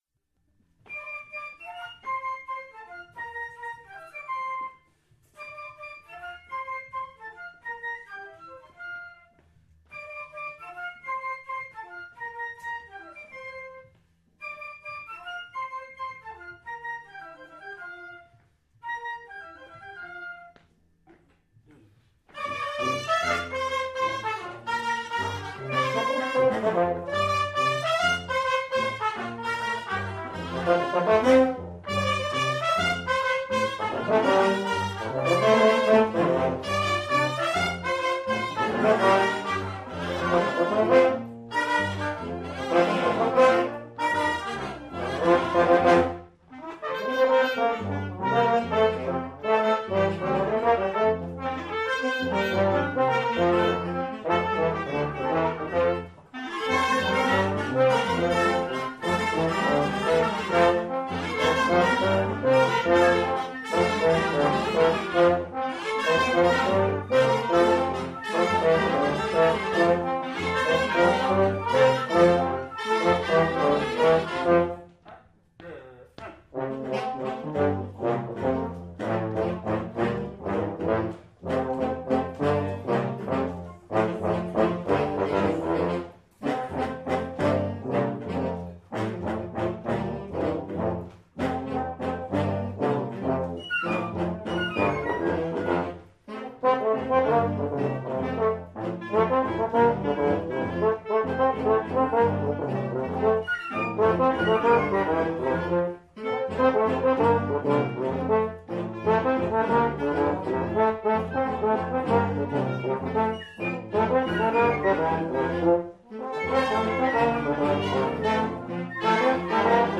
Enregistrements Audio lors du 1er stage 2014